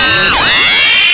RbtSecSirenD.wav